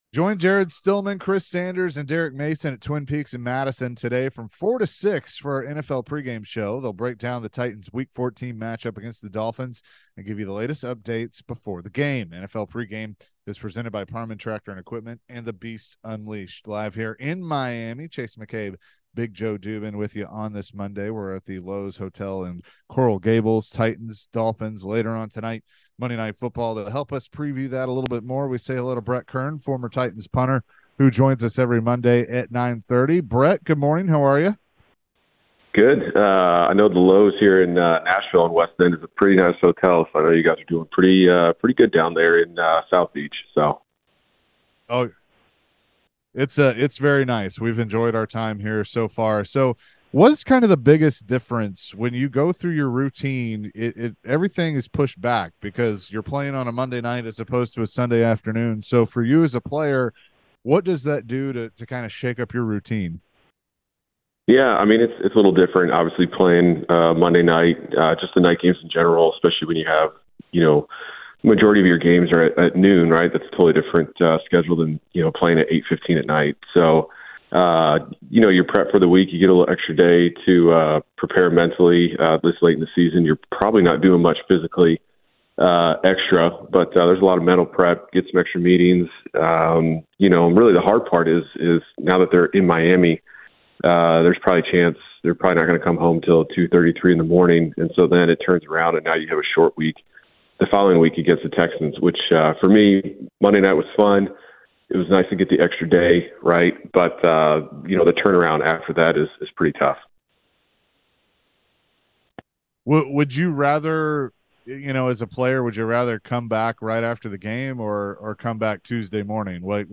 Former Titans punter Brett Kern joined the show and previewed tonight’s game between the Titans and the Dolphins. Later in the conversation, Brett talked about the firing of Craig Aukerman.